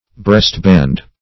Search Result for " breastband" : The Collaborative International Dictionary of English v.0.48: Breastband \Breast"band`\ (-b[a^]nd`), n. A band for the breast.